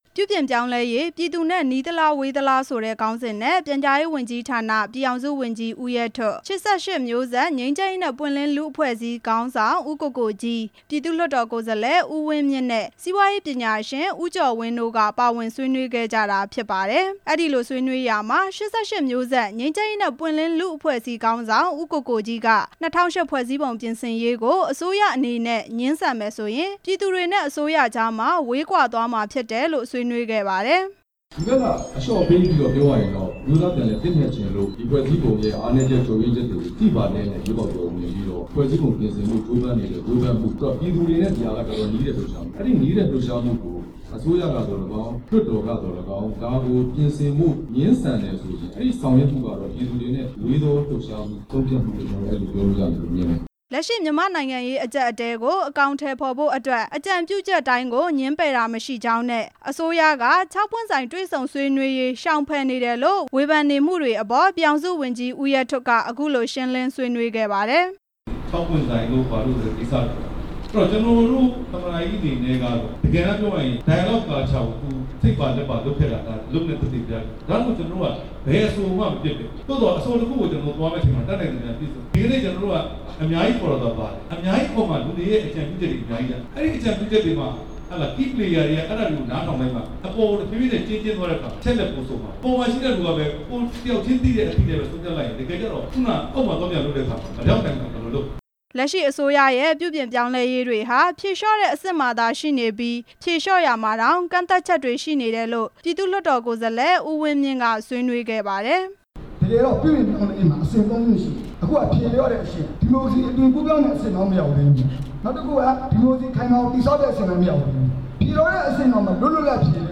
ပြုပြင်ပြောင်းလဲရေး ပြည်သူနဲ့ နီးသလား-ဝေးသလား ခေါင်းစဉ် ဆွေးနွေးချက်